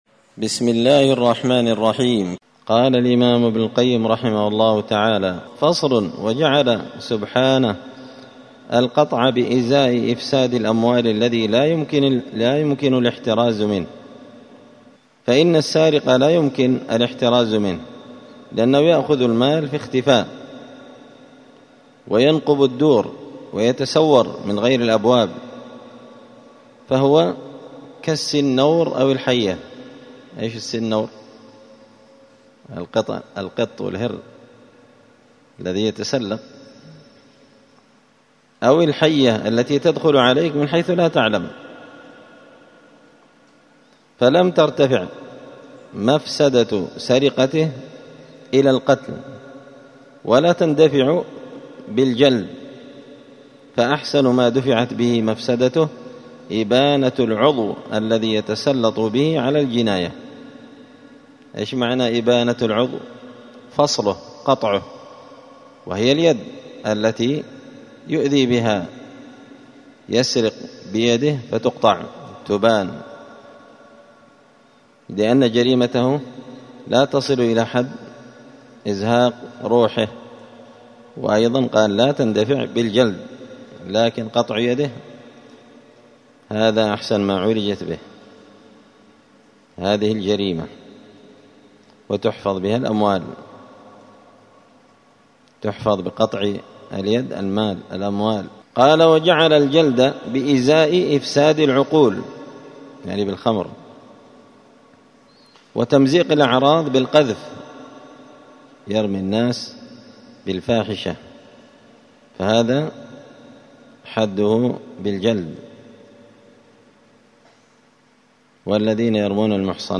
دار الحديث السلفية بمسجد الفرقان بقشن المهرة
*الدرس الحادي والخمسون (51) تابع لفصل وعقوبات الذنوب نوعان شرعية وقدرية*